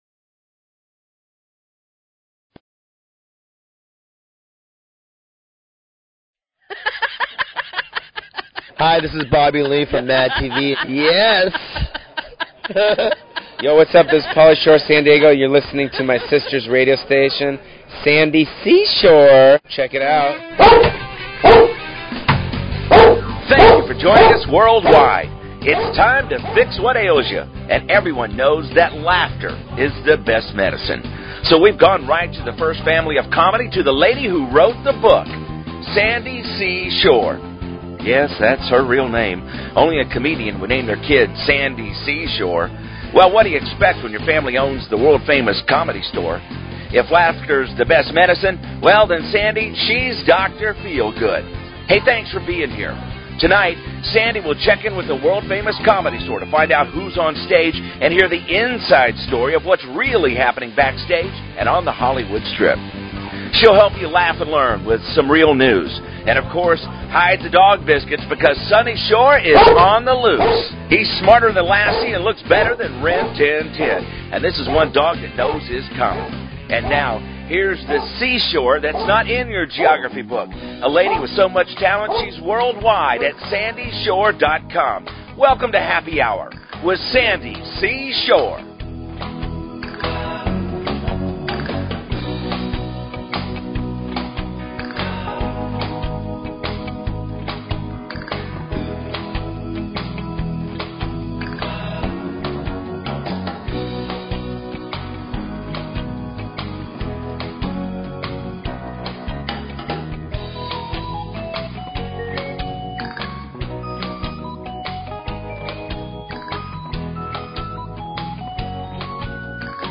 Talk Show Episode, Audio Podcast, Happy_Hour_Radio and Courtesy of BBS Radio on , show guests , about , categorized as